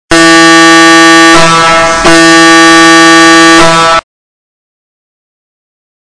LKW Kompr.-Trompete ohne Ventil
• 116dB bei 8.5 Bar
Abmessungenø 140 x 560mmFrequenz210HzLautstärke114dB/6 Bar, 116dB/8.5 Bar, 118dB/12 Bar